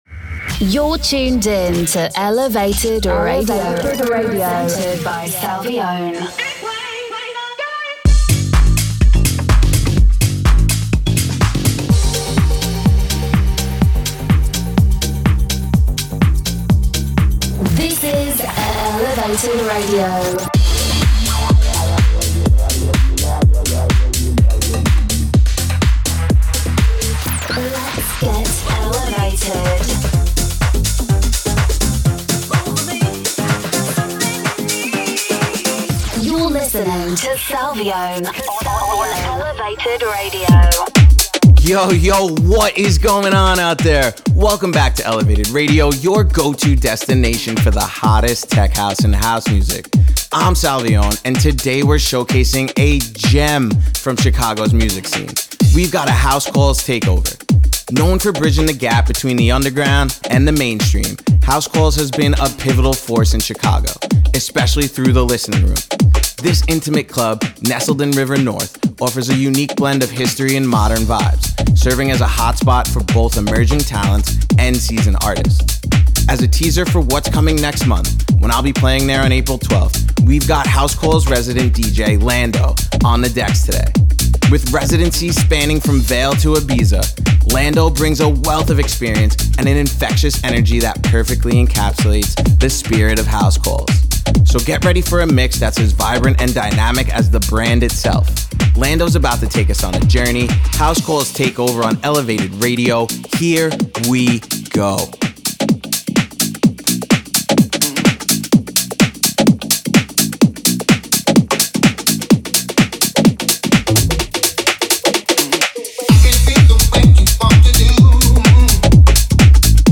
The weekly radio show